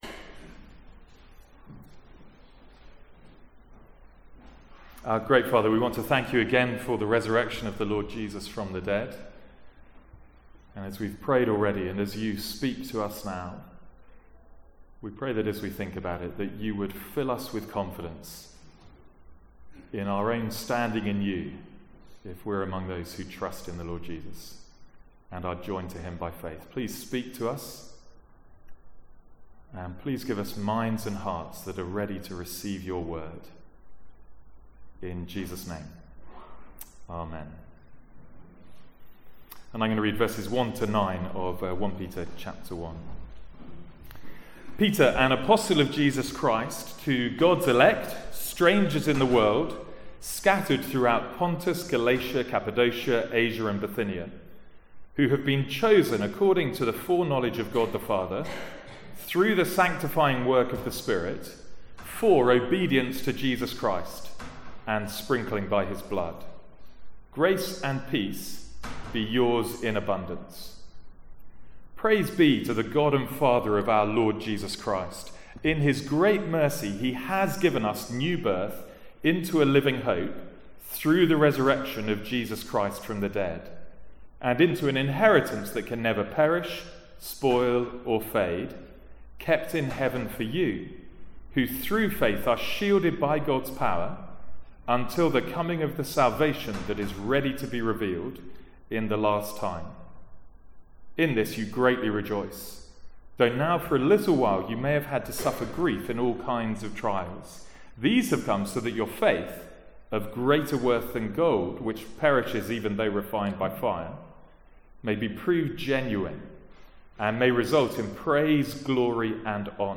From the Sunday morning series in 1 Peter.